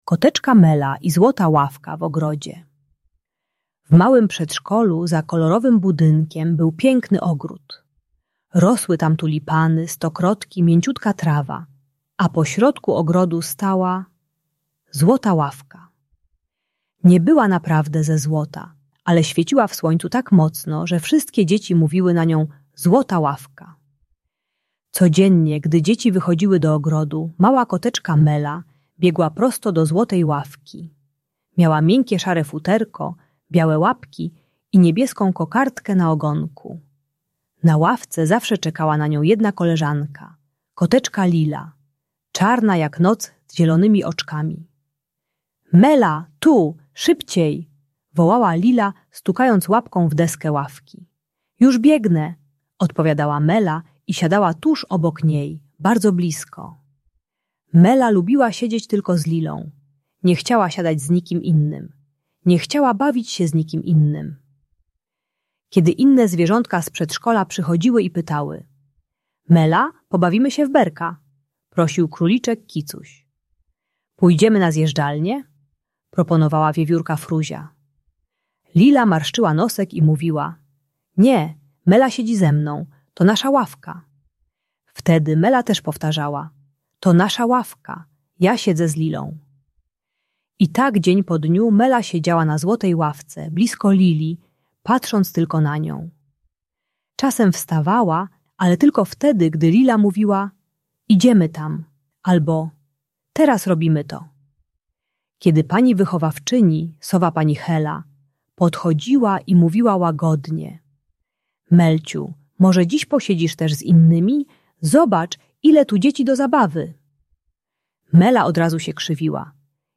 Audiobajka o relacjach rówieśniczych.